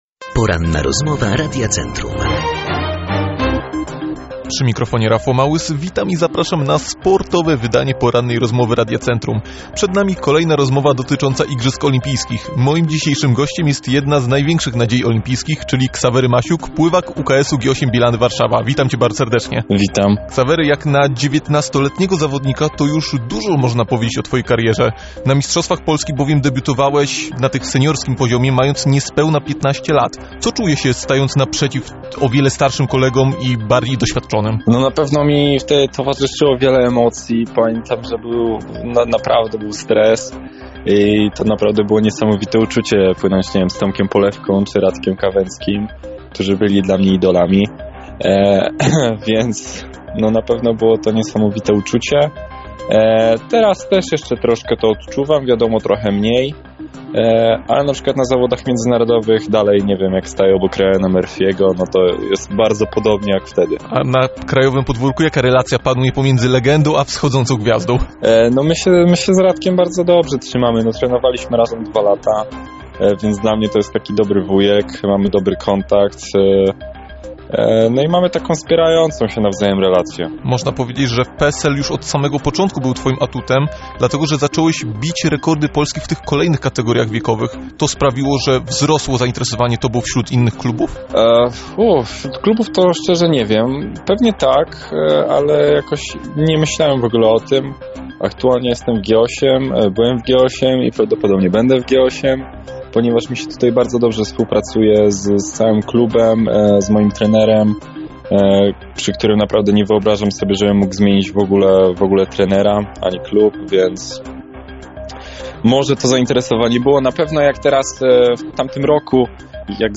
Ksawery Masiuk Ksawery Masiuk był gościem dzisiejszej Porannej Rozmowy Radia Centrum. W niej opowiedział o początkach swojej kariery. Wróciliśmy także wspomnieniami do jego największych sukcesów.
ROZMOWA-5.mp3